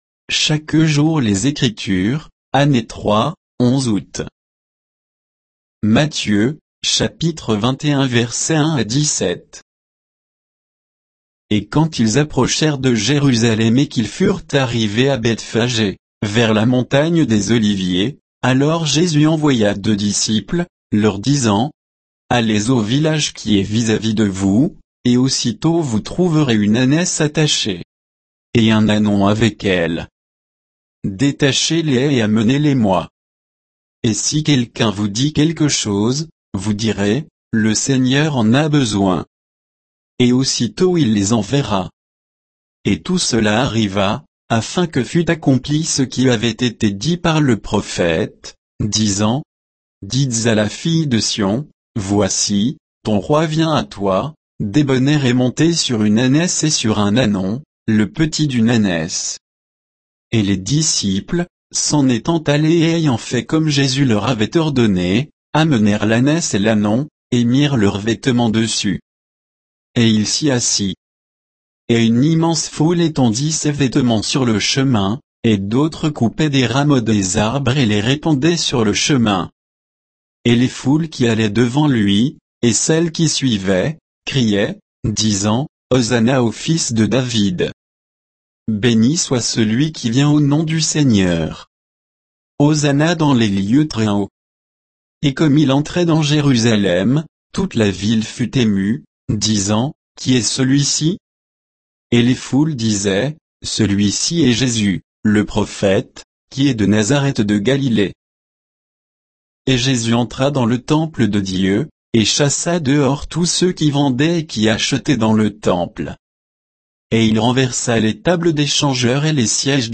Méditation quoditienne de Chaque jour les Écritures sur Matthieu 21, 1 à 17